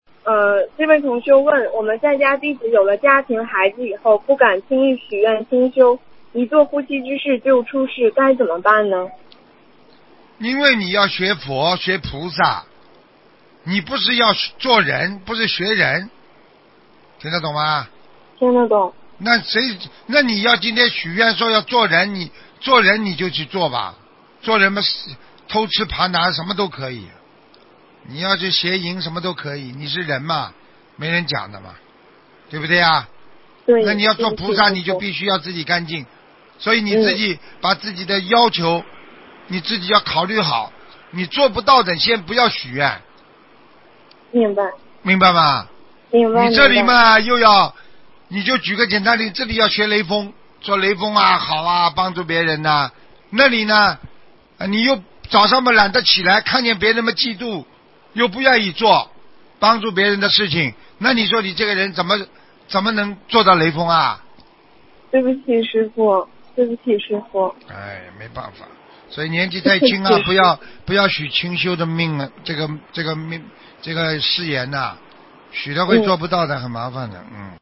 女听众： 师父您好！